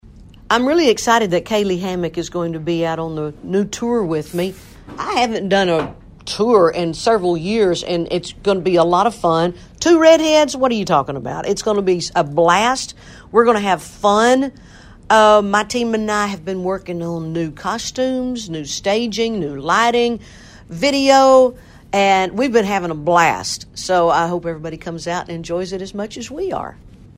Press play below to hear what she had to say about it!